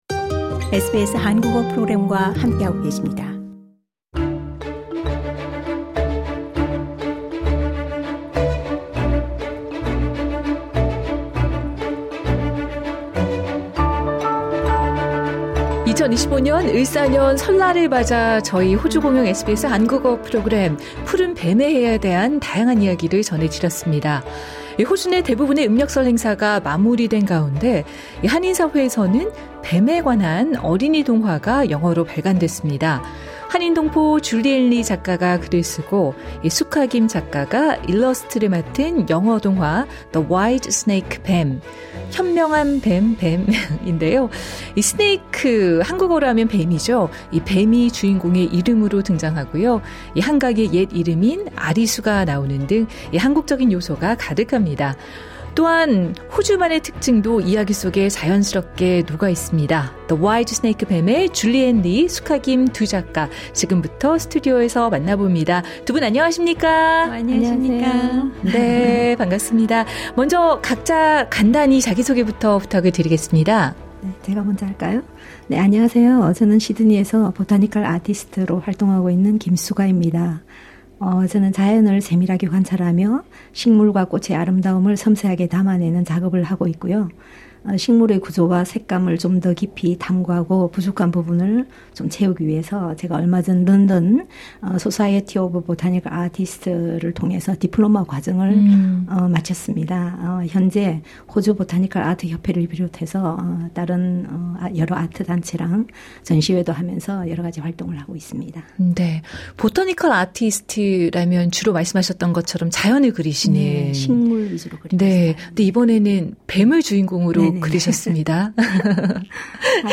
인터뷰: 음력설을 맞아 ‘종로’에서 ‘라이드’ 시로 날아온 ‘파란용 미르’